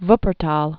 (vpər-täl)